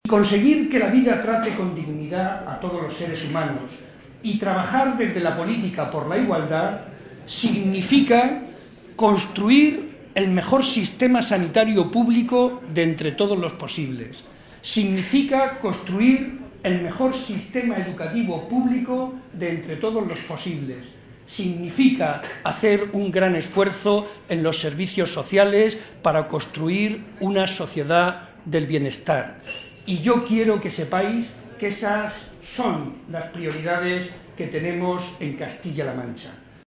Estas palabras fueron pronunciadas por Barreda en el tradicional vino de Navidad organizado por la Agrupación Socialista de Ciudad Real, momento en el que aprovechó para reivindicar el papel fundamental de la educación para lograr los valores anteriormente mencionados.
Vino navideño PSOE Ciudad Real